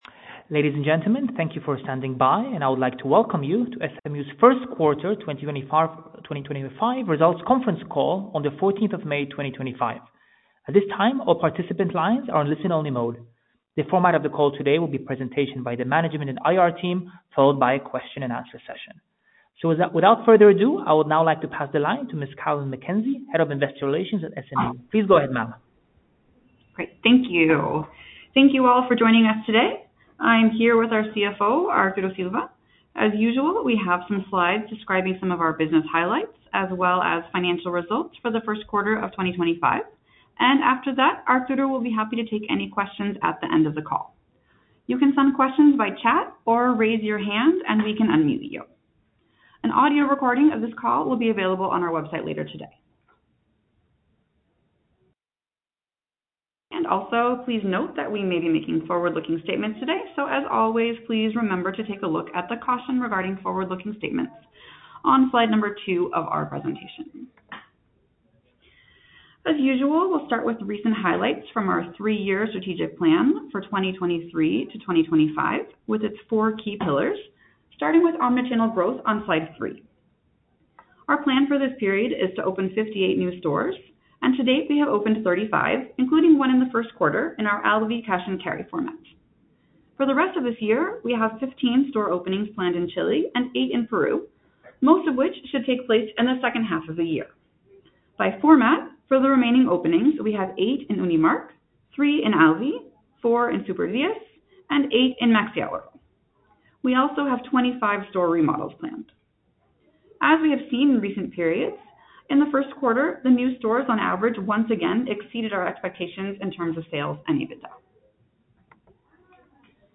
Earnings Call 1Q25
Conference_Call_Audio_1T25.mp3